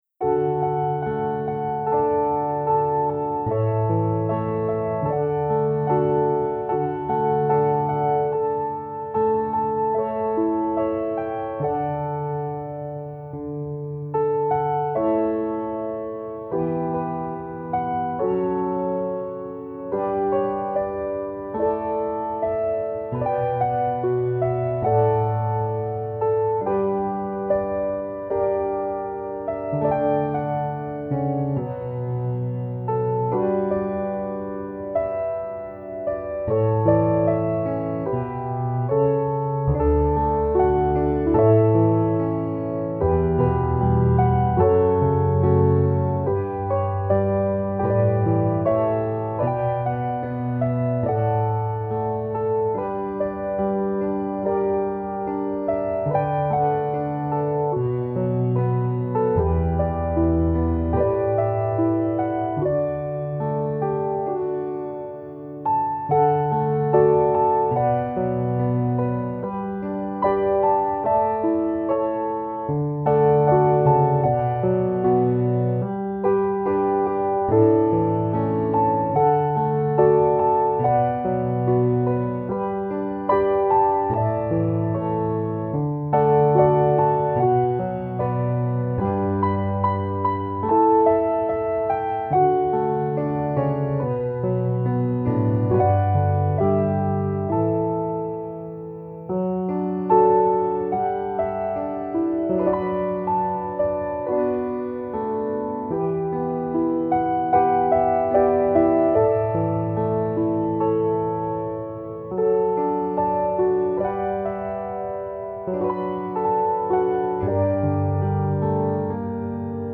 Solo Piano Melody
Instrumental